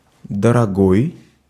Ääntäminen
GenAm: IPA : /ɪkˈspɛnsɪv/